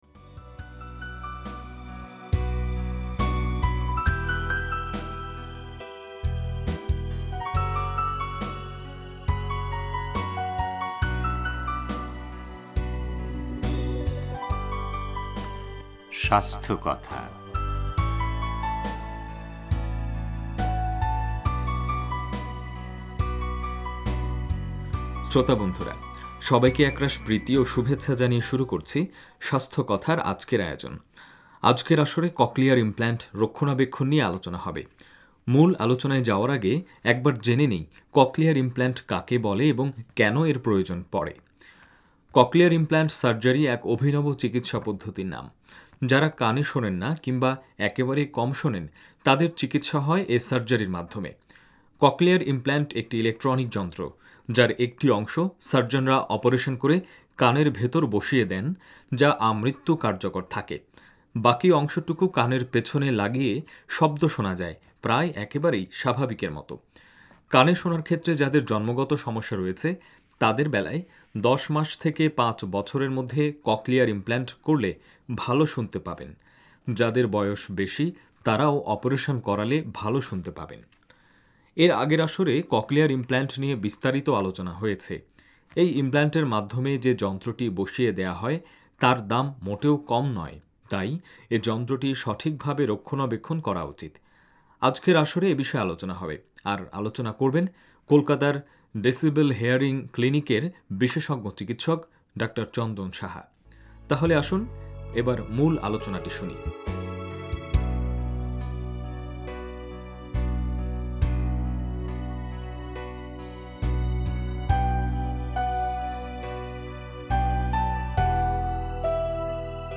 রেডিও তেহরান